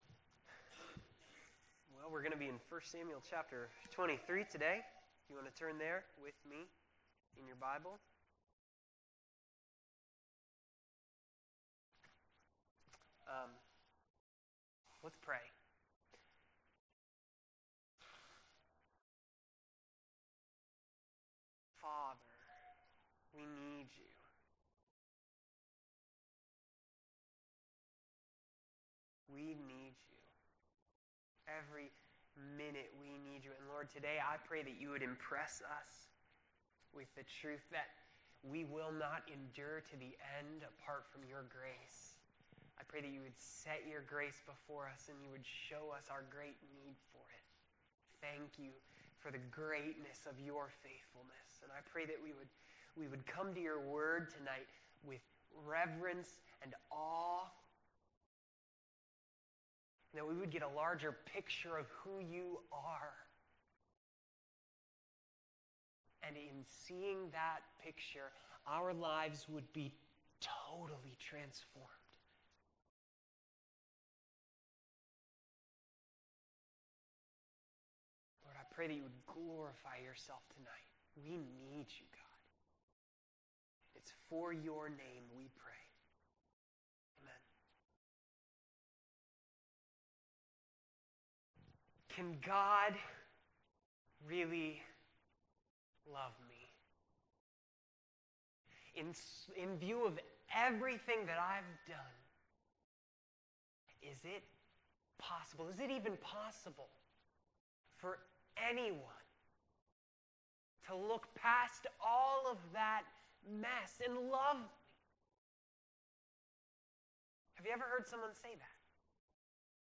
April 10, 2016 Evening Study | Vine Street Baptist Church
This was the next message in multi-part sermon series on the book of 1st Samuel.